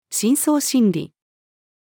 深層心理-female.mp3